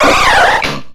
Cri de Colossinge dans Pokémon X et Y.